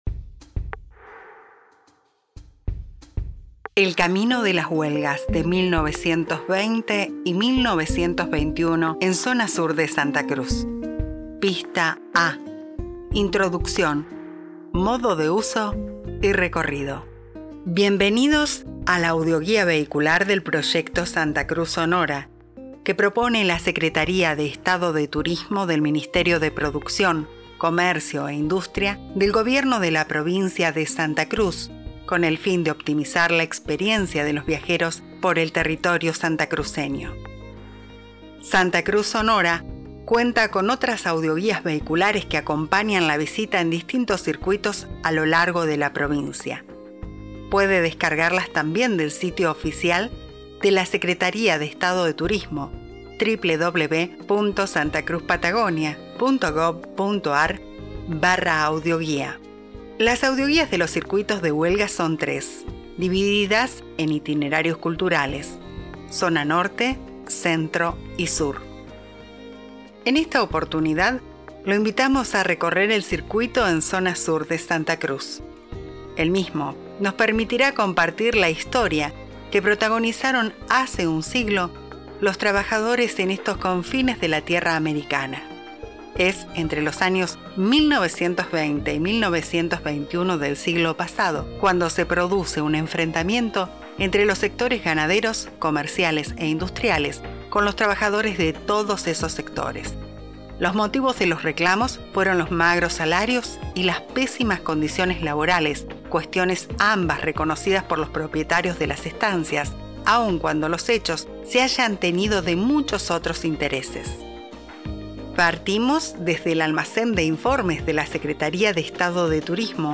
Audioguía Vehicular Huelgas Patagónicas